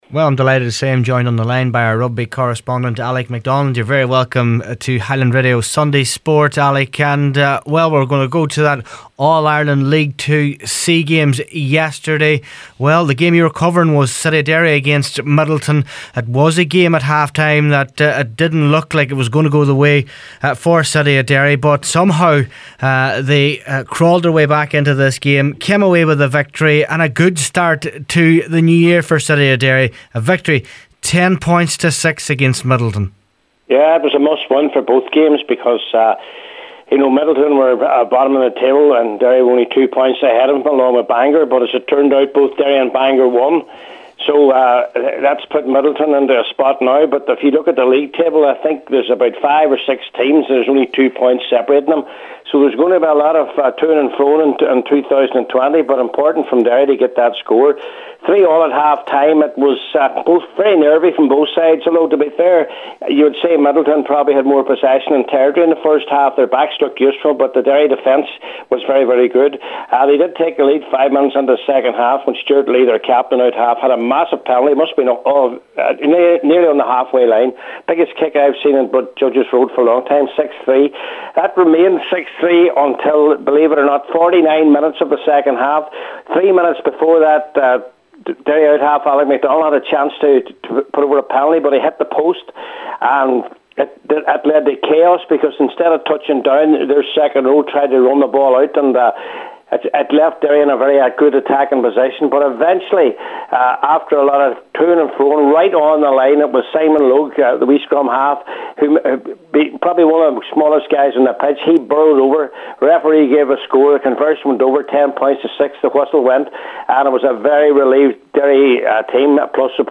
Rugby correspondent